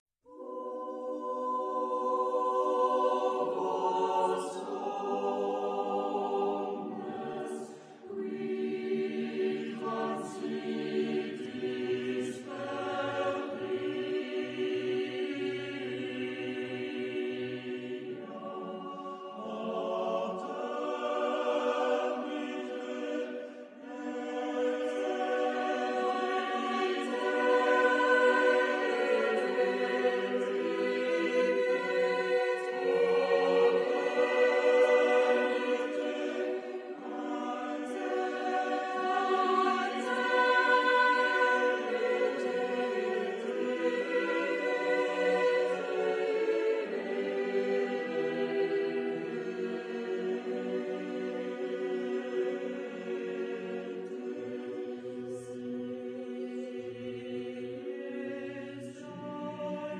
Genre-Style-Form: Motet ; Sacred ; Renaissance
Type of Choir: SATB  (4 mixed voices )
Tonality: C minor